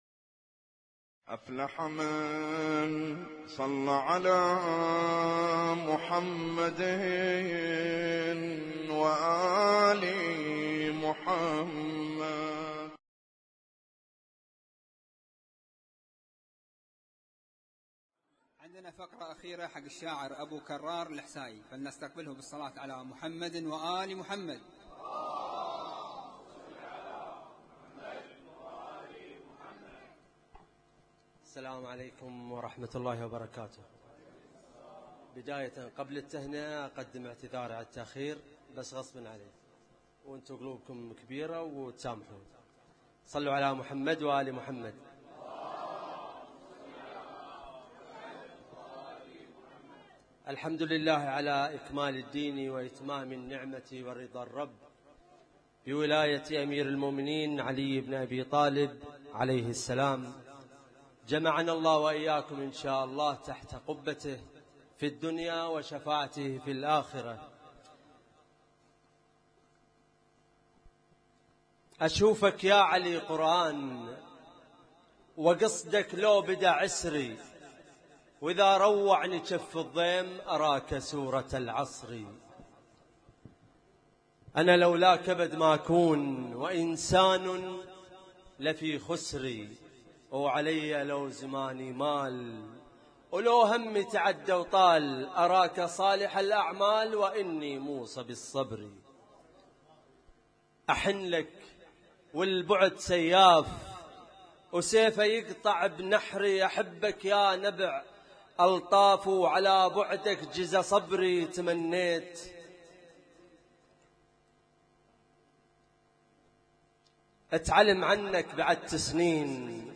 اسم التصنيف: المـكتبة الصــوتيه >> المواليد >> المواليد 1436